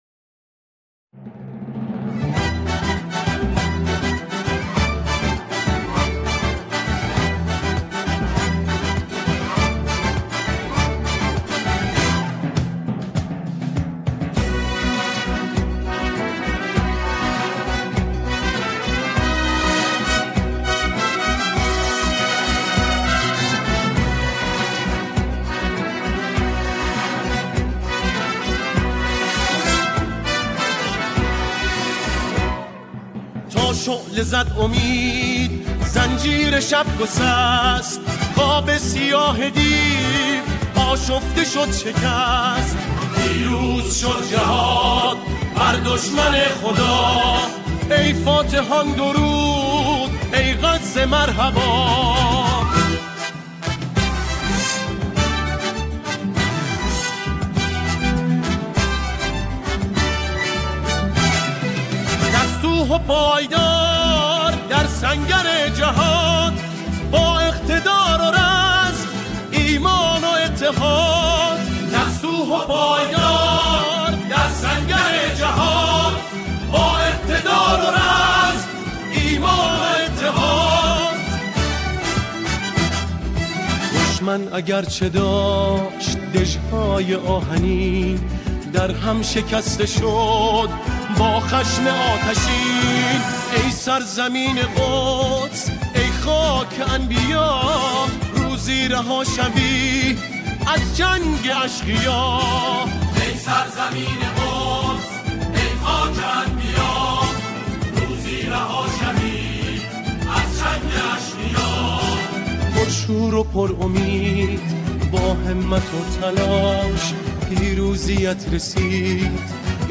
در این قطعه، آن‌ها، شعری را درباره فلسطین می‌خوانند.